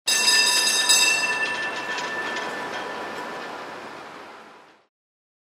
На этой странице собраны звуки трамваев: от ритмичного перестука колес по рельсам до характерных звонков и гула моторов.
Звук резкого трамвайного звонка